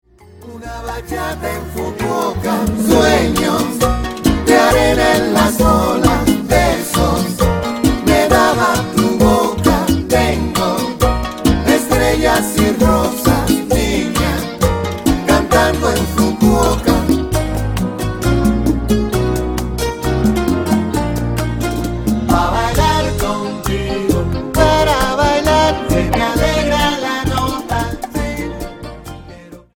Bachata Charts - Februar 2011